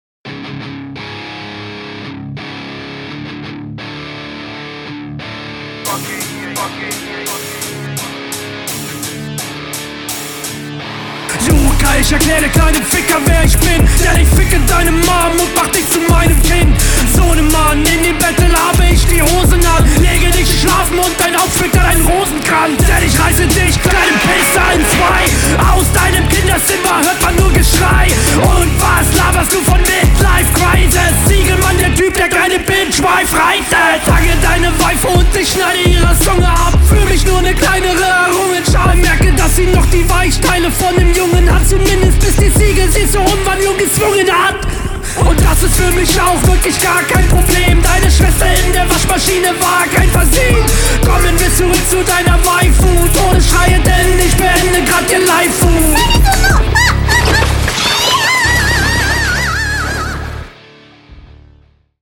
Flow: Absolut Solide, permanent im Takt (was man auch erwarten sollte).
Nice, das geht doch stimmlich schon viel mehr ab!